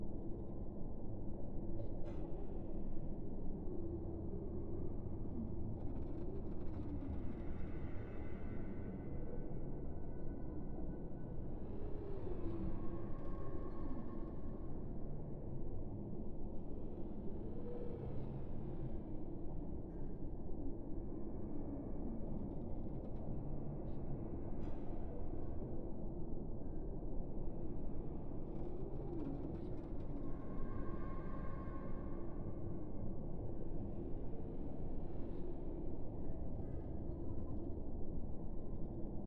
Minecraft Version Minecraft Version 1.21.5 Latest Release | Latest Snapshot 1.21.5 / assets / minecraft / sounds / ambient / nether / crimson_forest / ambience.ogg Compare With Compare With Latest Release | Latest Snapshot
ambience.ogg